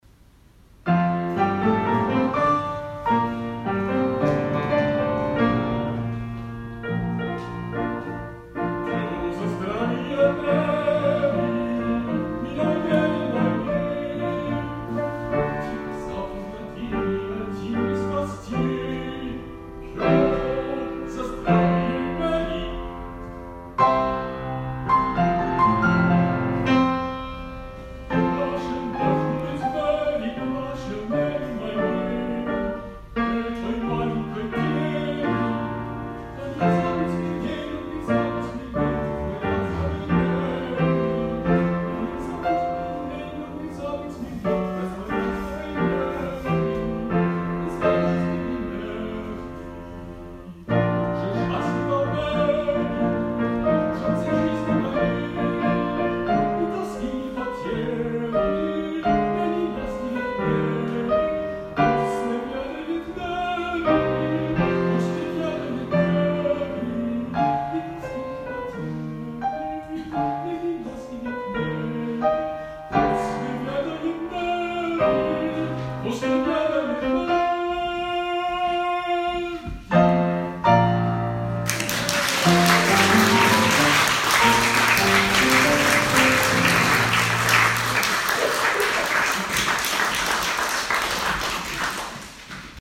Burns Night Supper -  25 January 2019